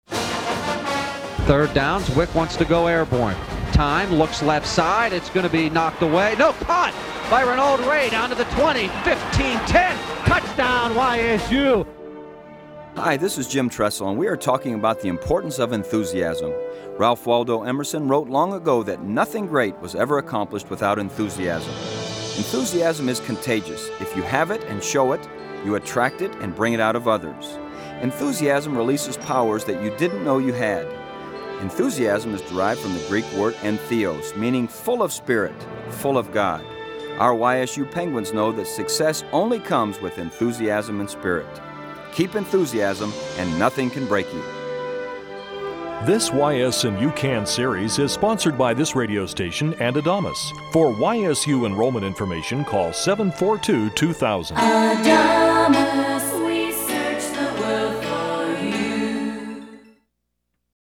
Radio Samples [.mp3]
This is just one of many radio commercials Jim Tressel recorded over a 7-year period as part of the YS & U Can Radio series before leaving for Ohio State.